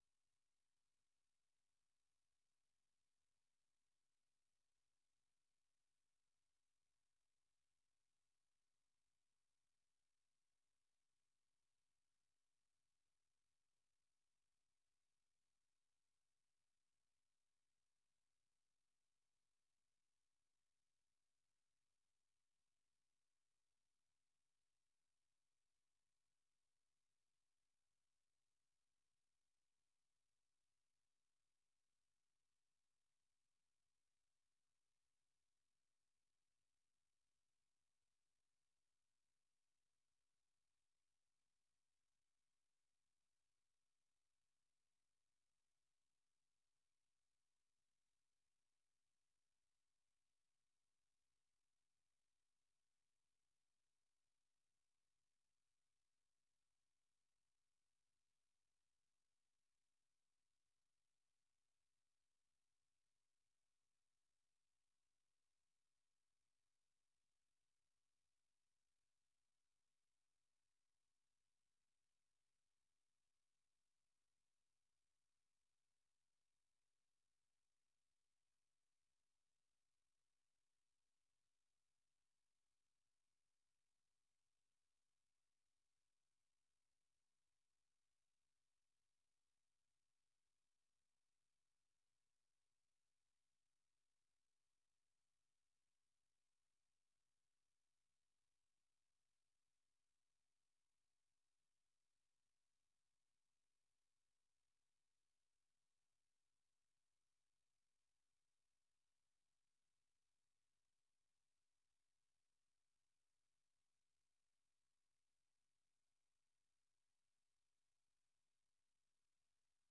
དགོང་དྲོའི་རླུང་འཕྲིན།